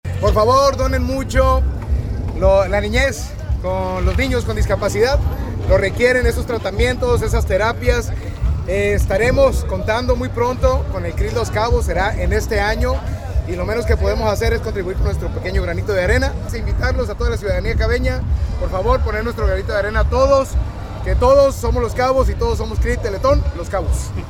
Durante la colecta, el presidente municipal Christian Agúndez Gómez hizo un llamado directo a la solidaridad de la ciudadanía.
Alcalde-Christian-Agundez-Crucero-Teleton-2025-CSL.mp3